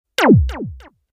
laser4.ogg